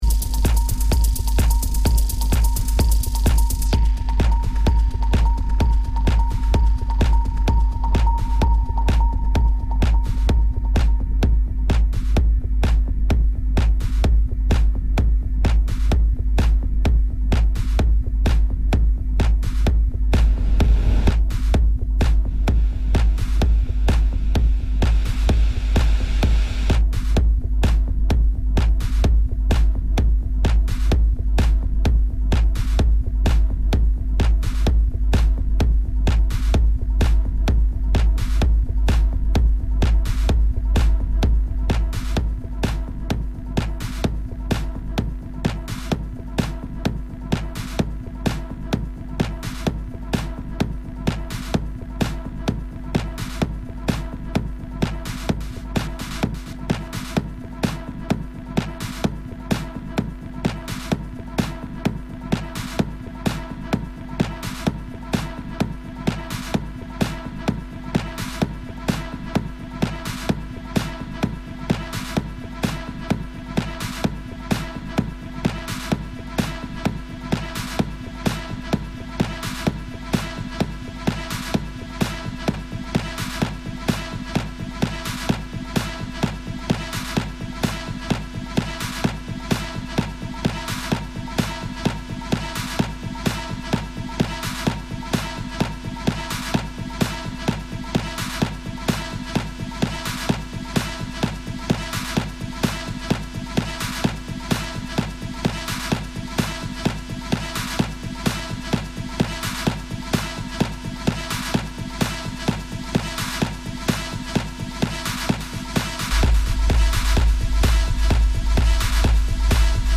live recorded set
exclusive interview